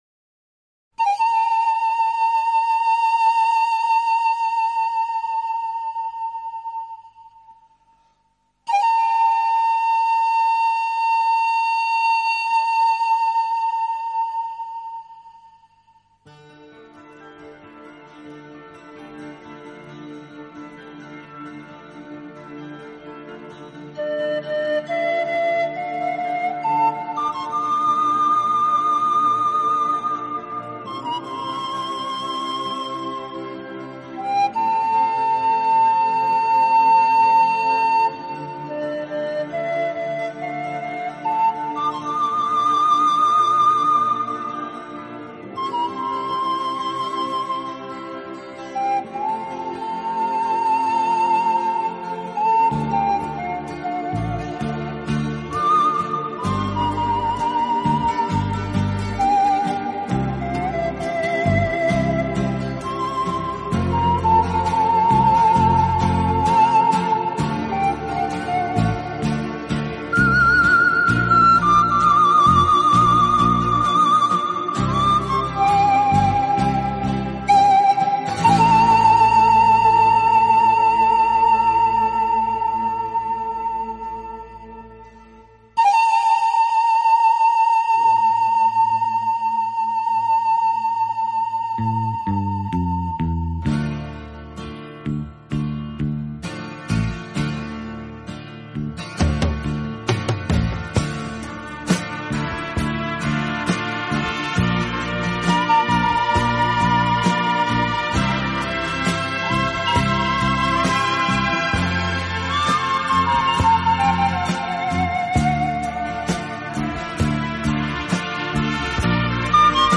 【排箫专辑】